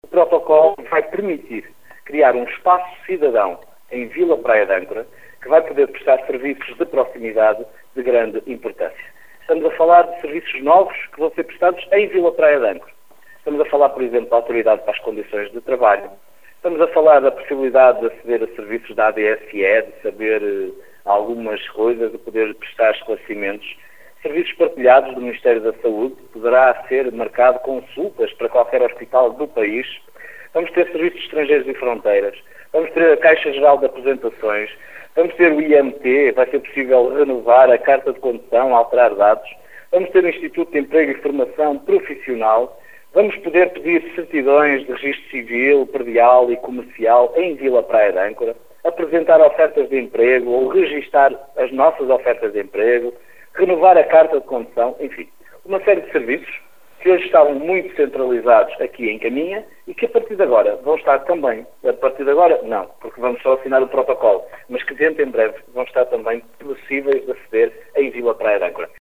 A criação deste espaço trará benefícios em termos de desburocratização e poupança de tempo útil para a população de Vila Praia de Âncora e de todo o Vale do Âncora, como destaca o autarca Miguel Alves